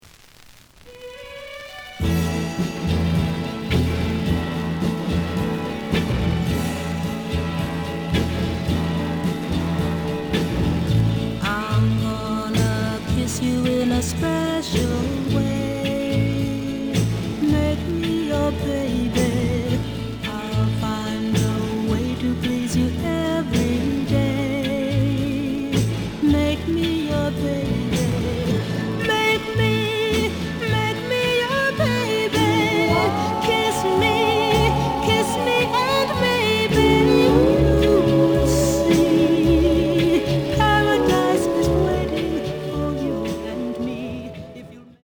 The audio sample is recorded from the actual item.
●Genre: Soul, 60's Soul
Some noise on both sides.)